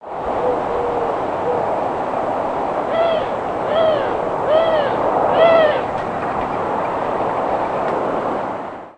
Eurasian Collared-Dove Streptopelia decaocto
Flight call description No known flight call but a nasal, growling, slightly descending "nraaaar", often repeated, is given in short flights.
Diurnal calling sequence:
Bird in short flight.
Gives "nraaaar" call while perched and in short flights during the day.